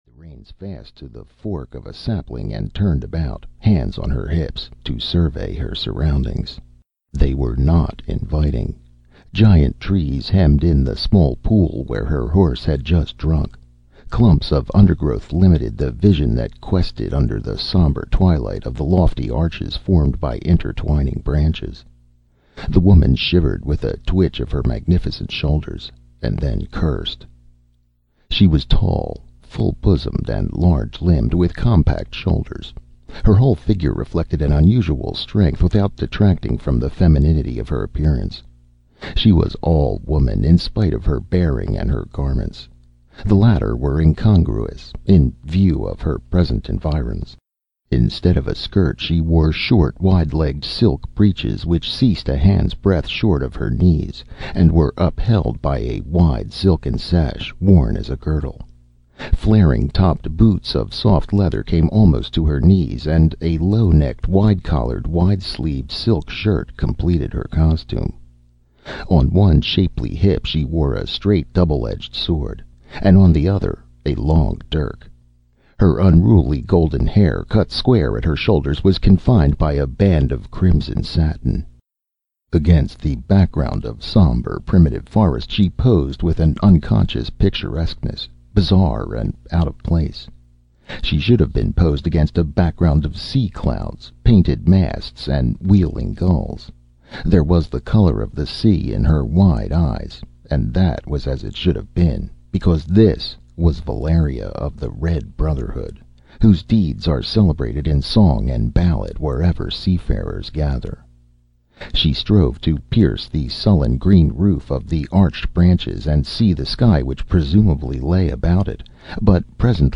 Red Nails (EN) audiokniha
Ukázka z knihy